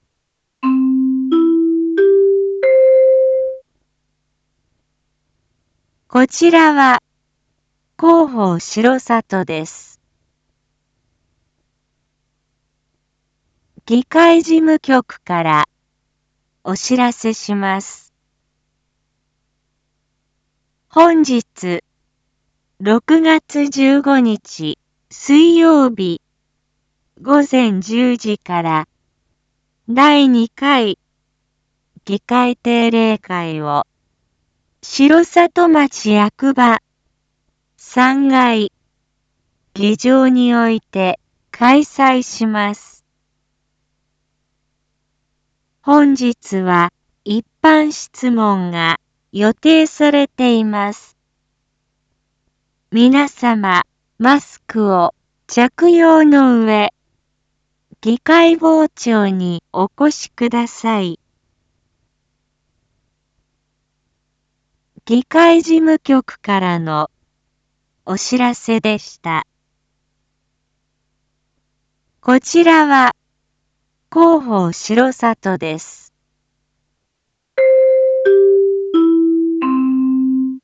Back Home 一般放送情報 音声放送 再生 一般放送情報 登録日時：2022-06-15 07:06:22 タイトル：R4.6.15 7時放送分 インフォメーション：こちらは広報しろさとです。